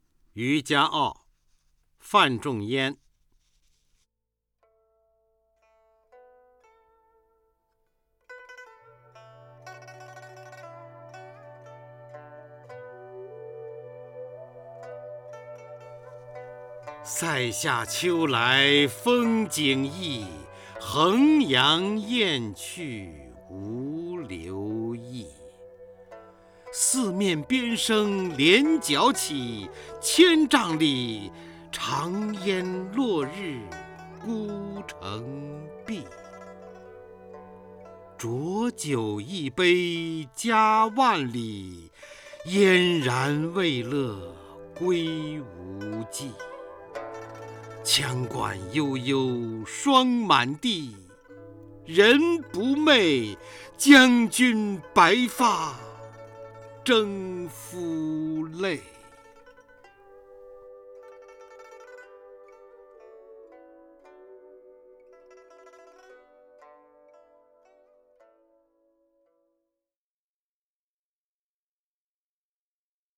方明朗诵：《渔家傲·塞下秋来风景异》(（北宋）范仲淹)
名家朗诵欣赏 方明 目录